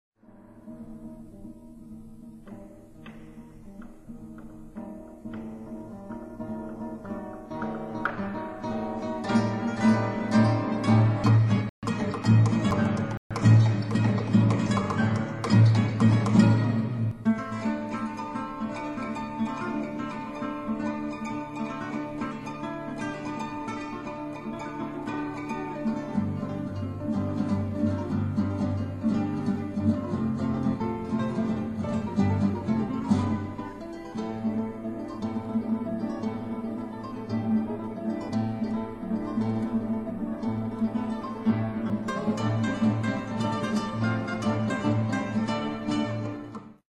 Gitarren solo